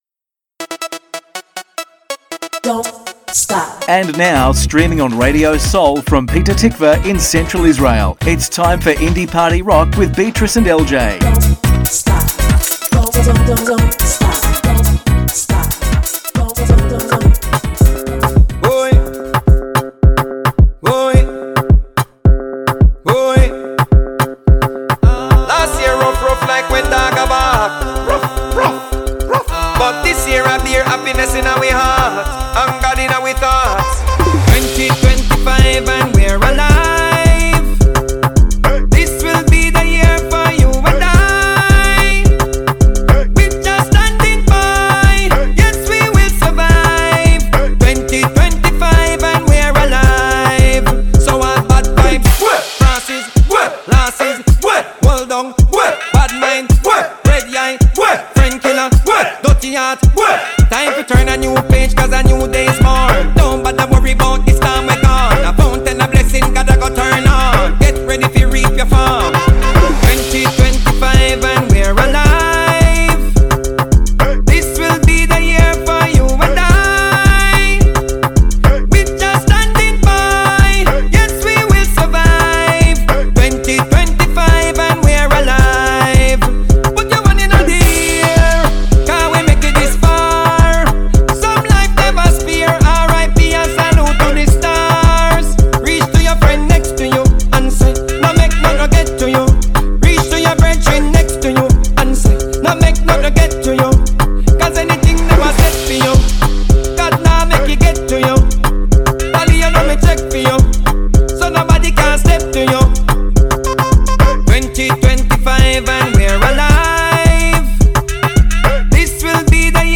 מוזיקת קאנטרי ואינדי עולמית - התכנית המלאה 28.2.25